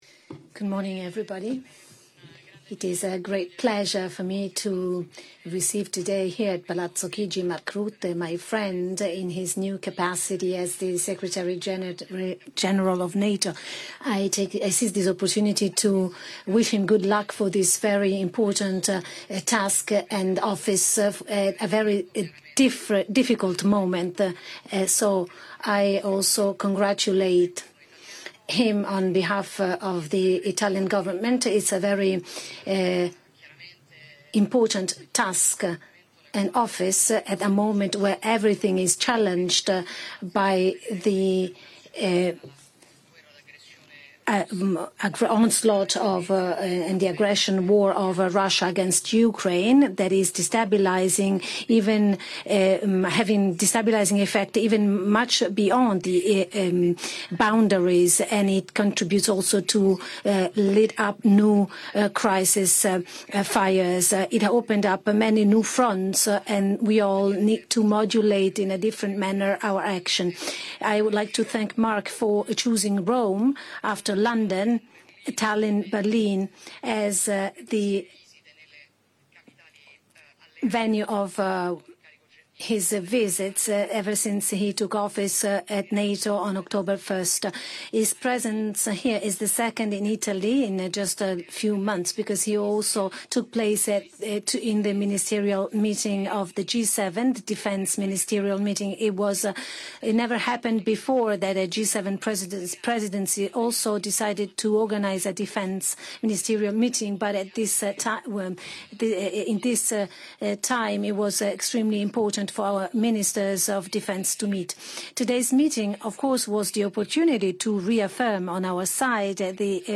ENGLISH - Joint press statements by NATO Secretary General Mark Rutte and the Prime Minister of Italy, Giorgia Meloni 05 Nov. 2024 | download mp3 ORIGINAL - Joint press statements by NATO Secretary General Mark Rutte and the Prime Minister of Italy, Giorgia Meloni 05 Nov. 2024 | download mp3